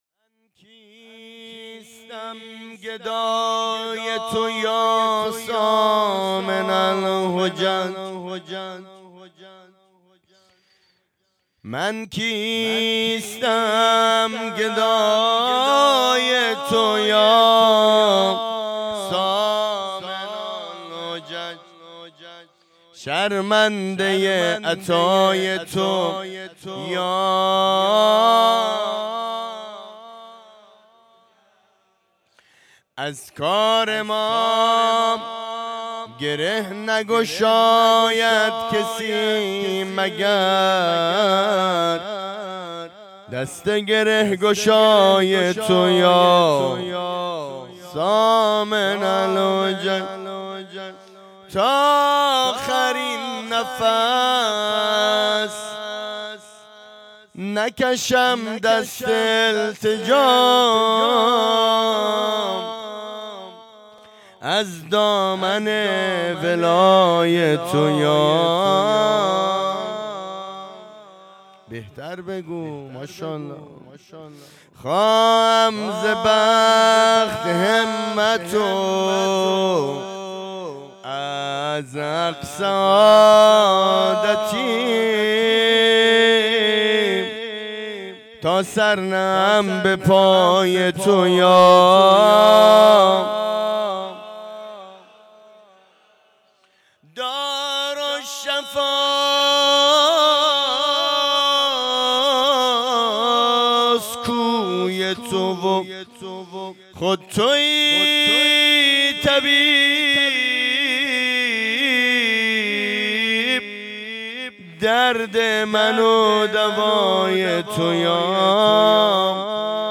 جشن میلاد امام رضا علیه السلام 1404